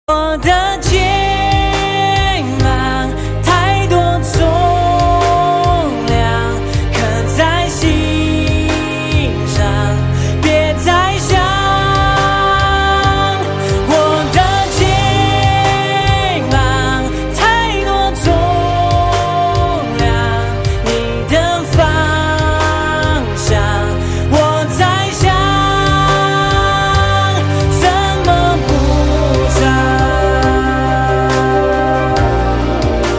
M4R铃声, MP3铃声, 华语歌曲 80 首发日期：2018-05-15 12:32 星期二